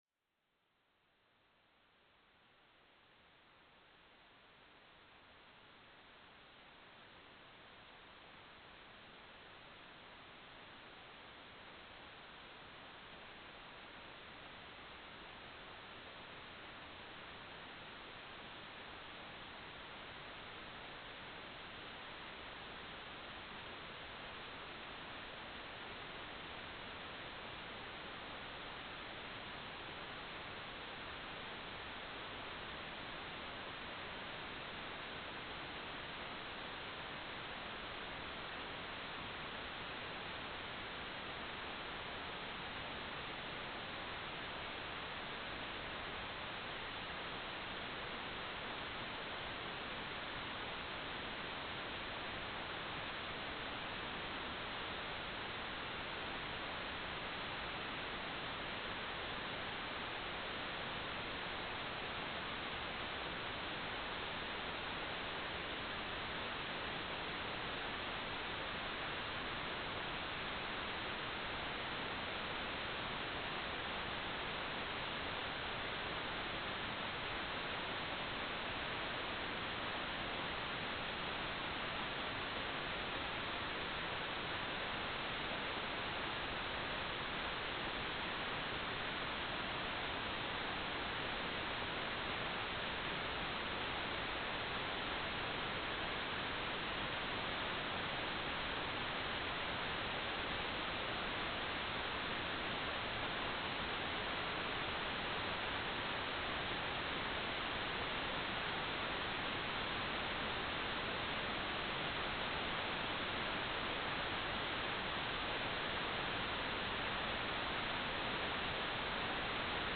"waterfall_status": "without-signal",
"transmitter_mode": "CW",